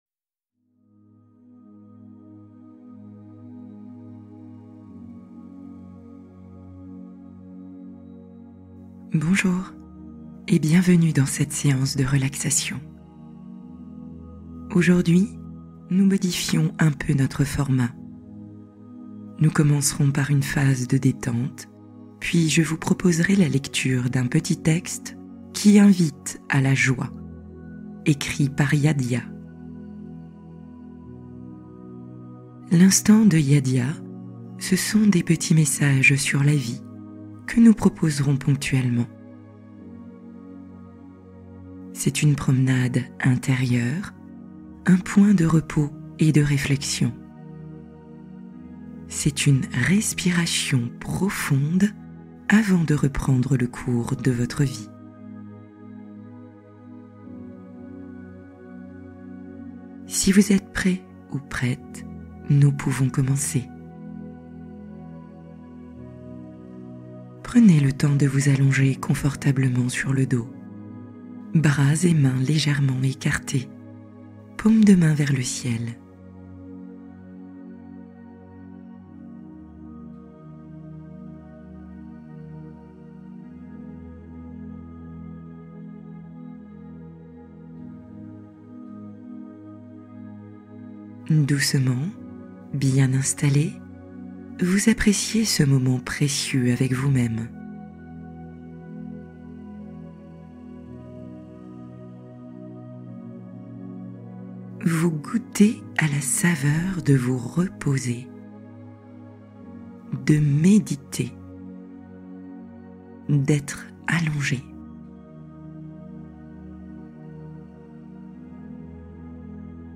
Revenir à l’essentiel : pause guidée pour se recentrer profondément